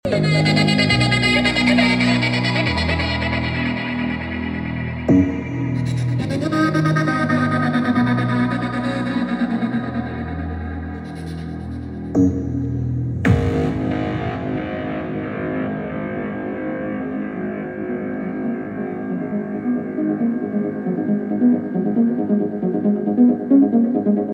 KARA double twelve speaker linear array, sound effects free download